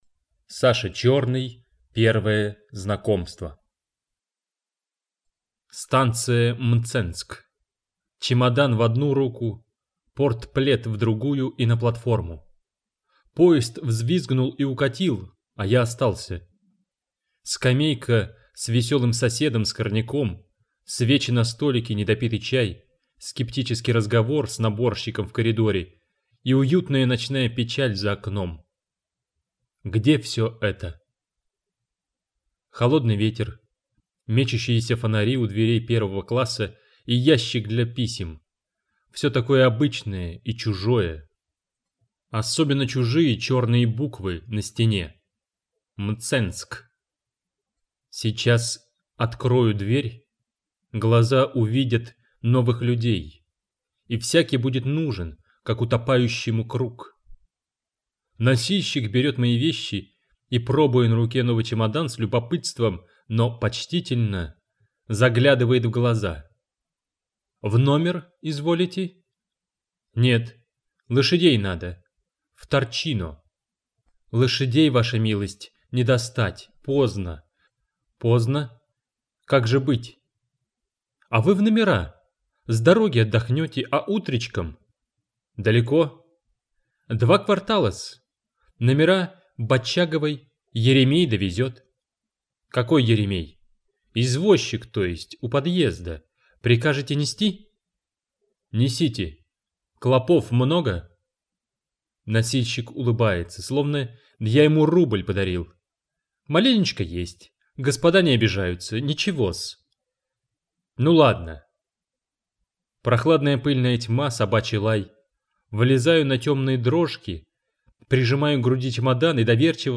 Аудиокнига Первое знакомство | Библиотека аудиокниг